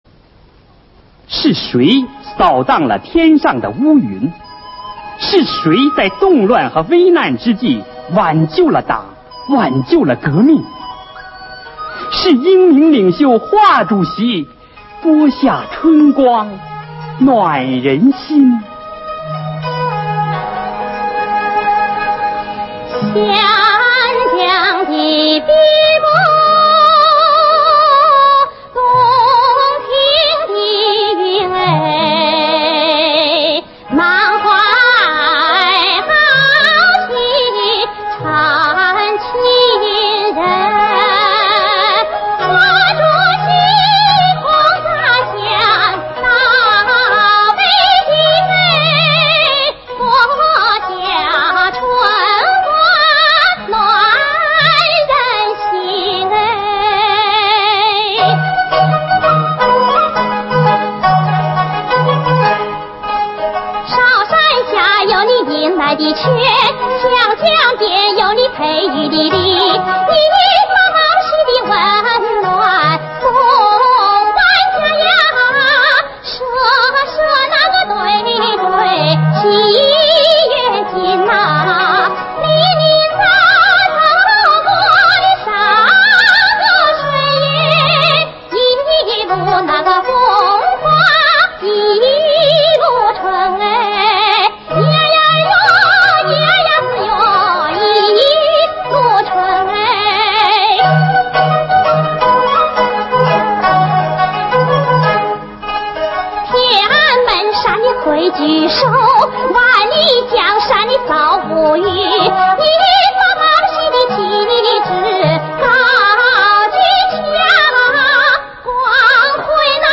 女声独唱